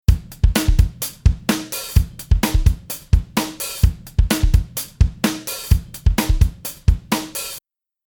Real drum sound parallel compression